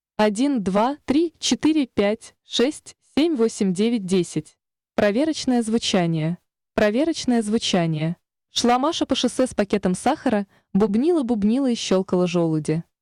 Сформировал в яндекс переводчике звуковой файл, в котором сделал много шипящих, свистящих и бубнящих звуков, закинул его на плейер. Плейер подключил к SSM, а выход SSM к линейному входу звуковой карты компьютера. На компе звук записывал с помощью Adobe Audition.
3. Резистор 200кОм = 75кОм, компрессия 5:1